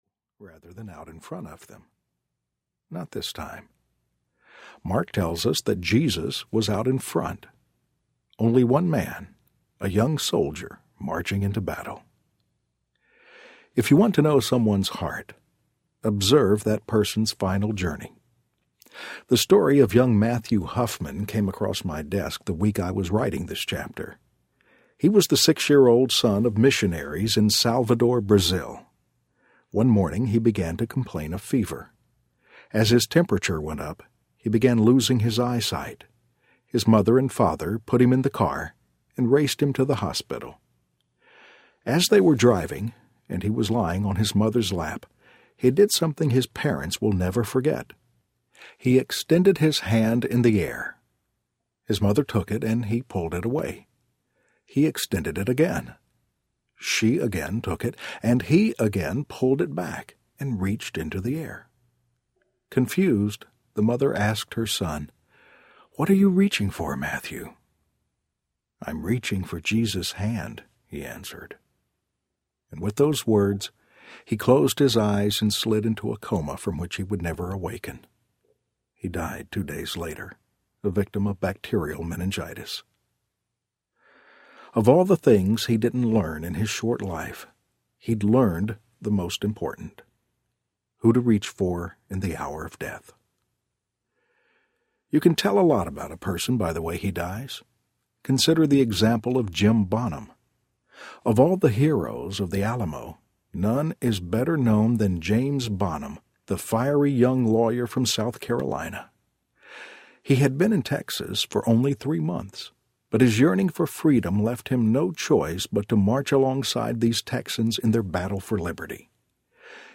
And the Angels Were Silent Audiobook
Narrator
5.8 Hrs. – Unabridged